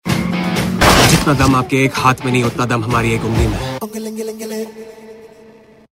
Dialogue Mp3 Tone